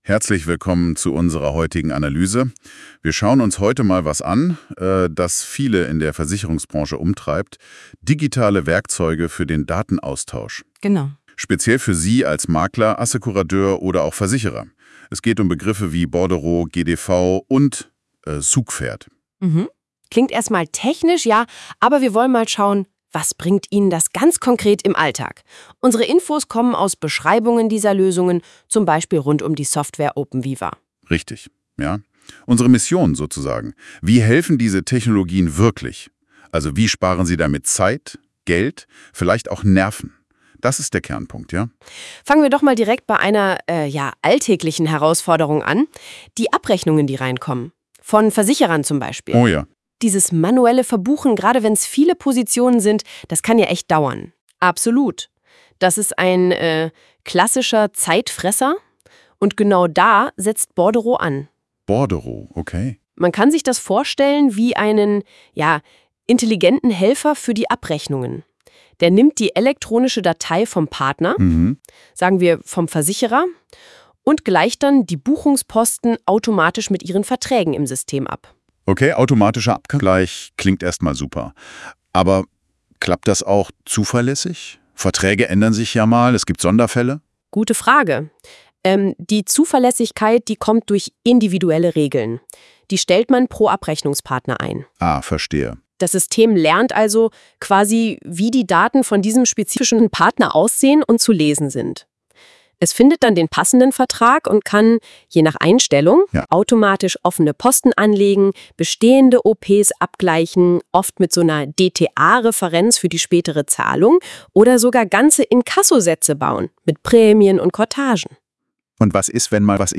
Erleben Sie unsere Modulgruppe "Datenaustausch" aus Sicht einer KI - in unserem neuen Podcast mbKompakt.
Was kann mir eine Standardsoftware für die Versicherungsbranche bieten? Praxisnah und verständlich erklärt uns die künstliche Intelligenz von Google NotebookLM die Modulgruppen von openVIVA c2.